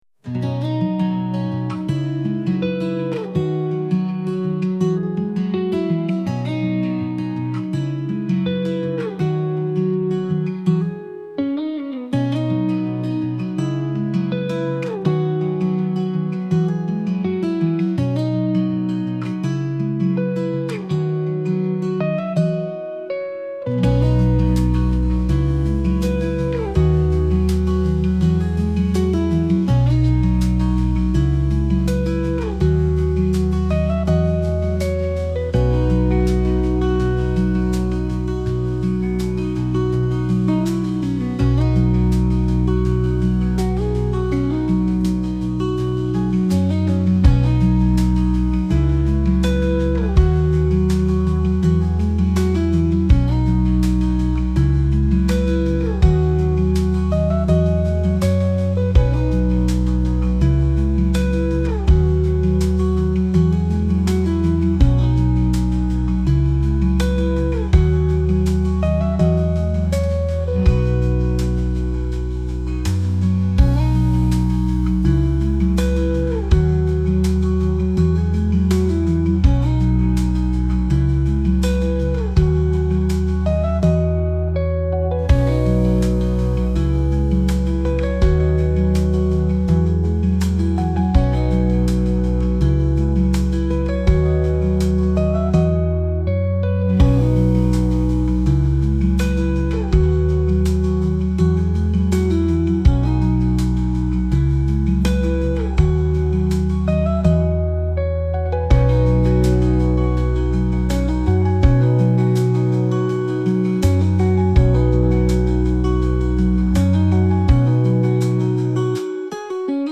どこか懐かしい夏 ギター 穏やか 青春